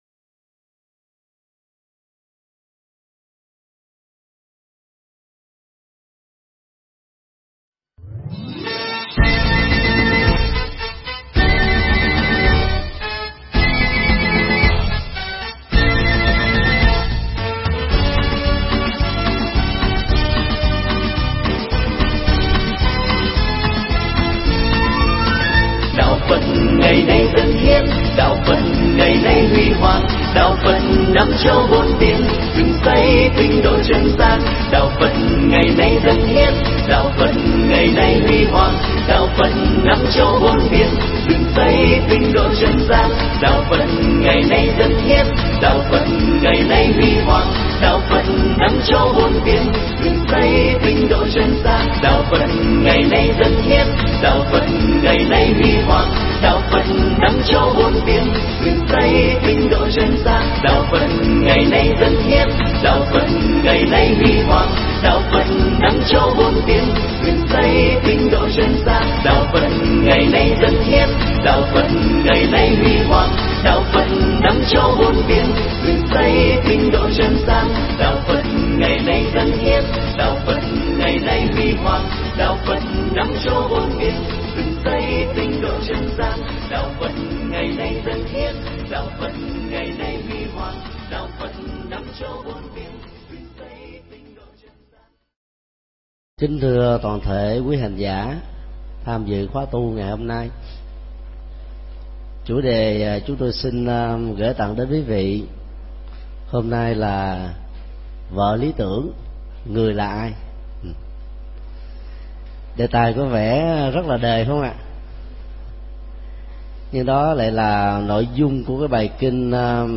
Tải mp3 Pháp thoại Kinh bảy loại vợ: Vợ lý tưởng – Người là ai ?
giảng tại chùa Ấn Quang ngày 13 tháng 06 năm 2010